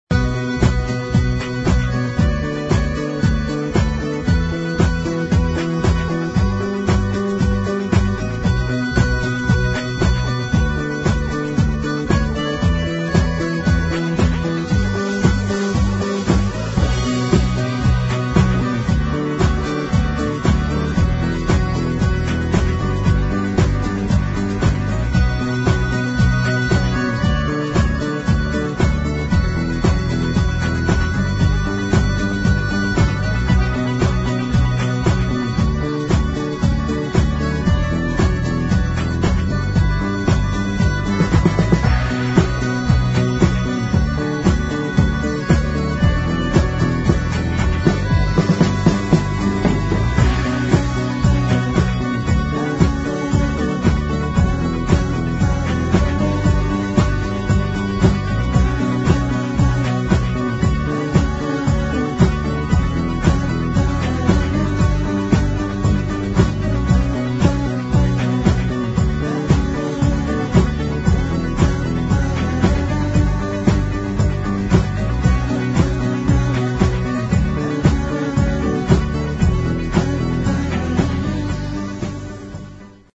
[ HOUSE / INDIE ]